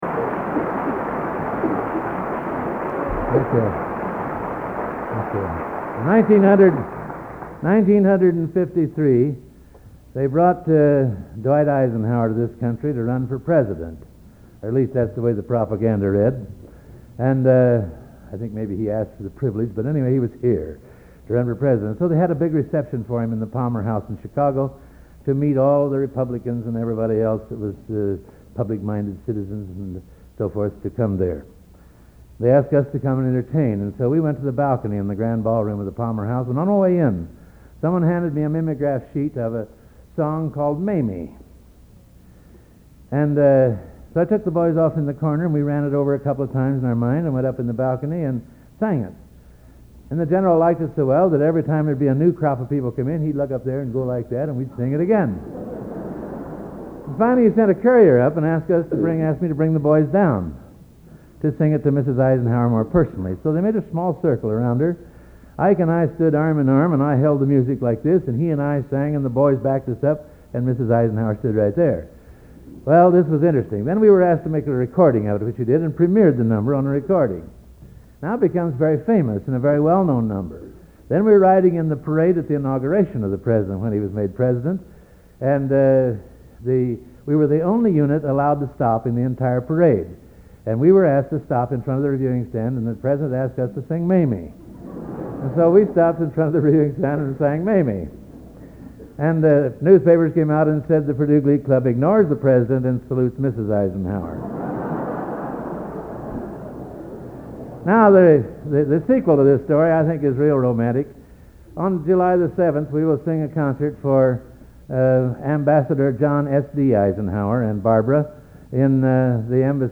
Collection: End of Season, 1971
Location: West Lafayette, Indiana
Genre: | Type: Director intros, emceeing